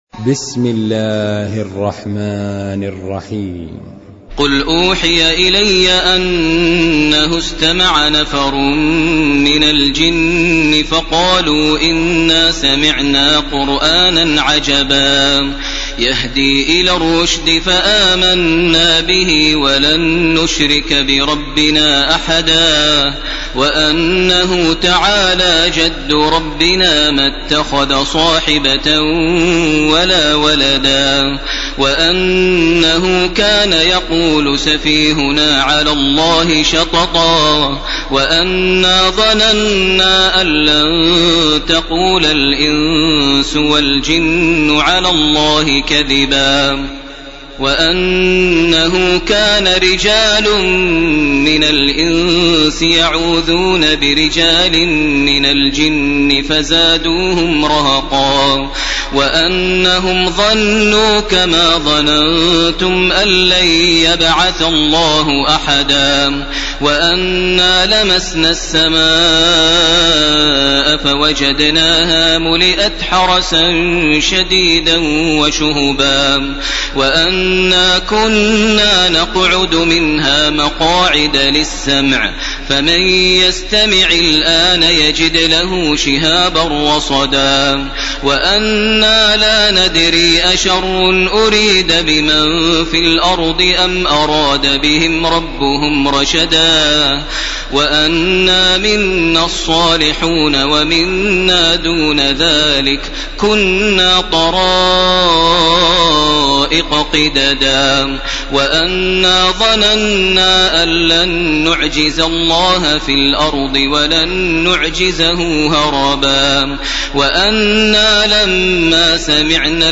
ليلة 28 من رمضان 1431هـ من سورة الجن إلى سورة المرسلات كاملة. > تراويح ١٤٣١ > التراويح - تلاوات ماهر المعيقلي